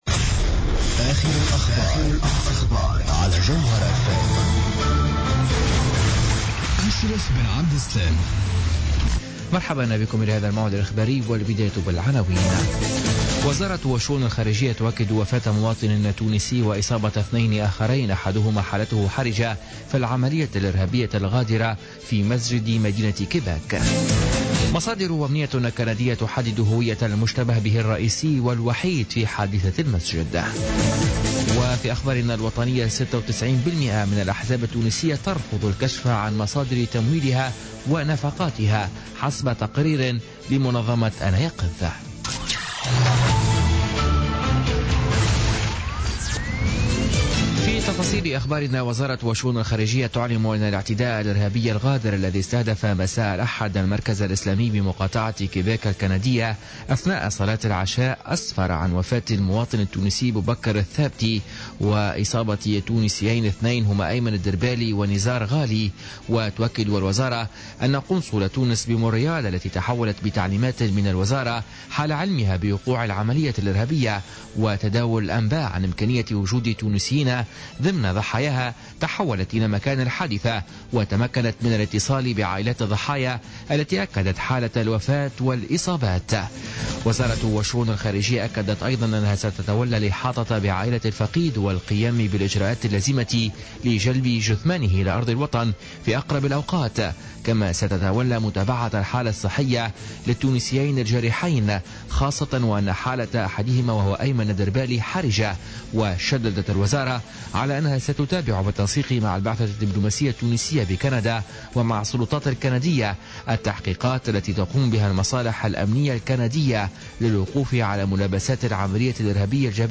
نشرة أخبار منتصف الليل ليوم الثلاثاء 31 جانفي 2017